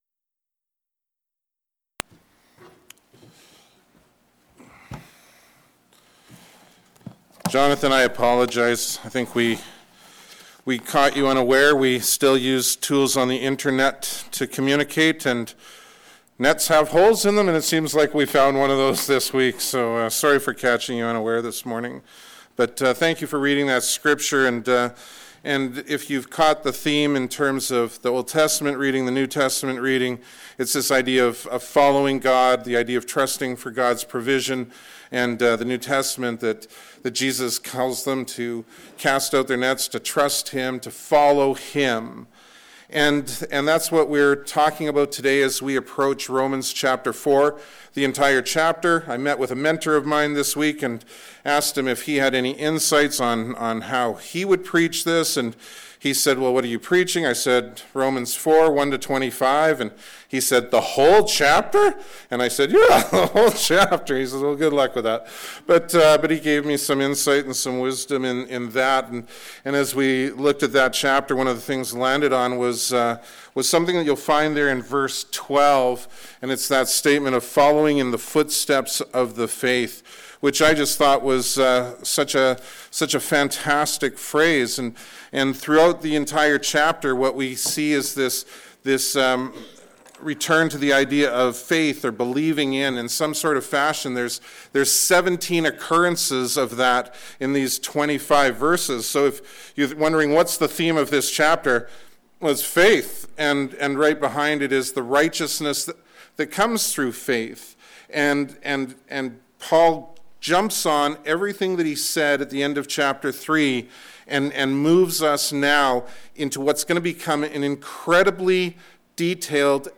2023 Following in the Footsteps of the Faith Preacher